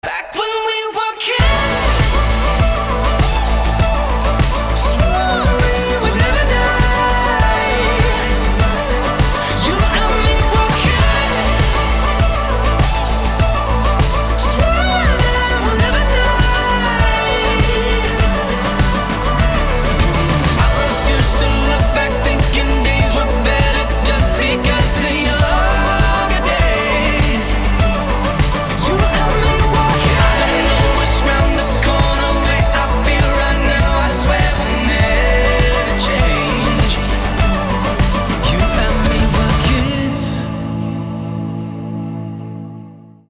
• Качество: 320, Stereo
мужской вокал
громкие
dance
Pop Rock